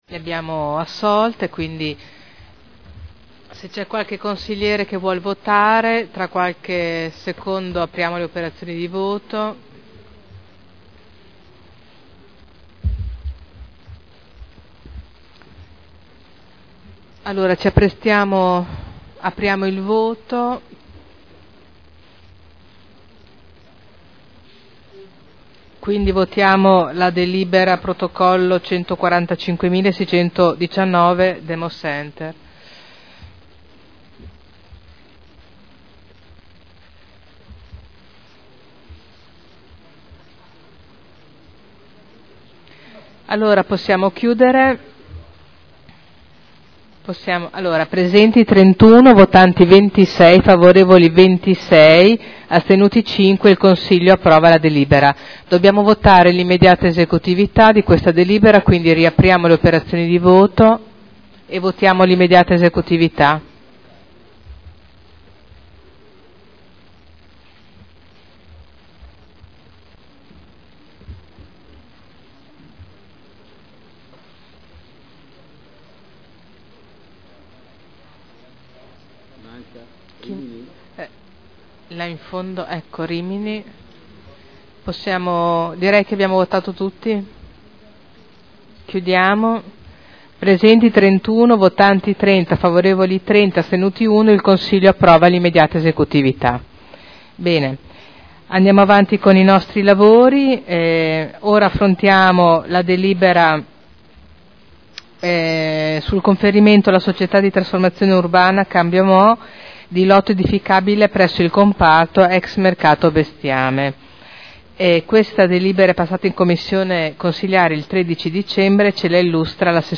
Presidente — Sito Audio Consiglio Comunale
Seduta del 22/12/2011. Mette ai voti proposta di deliberazione.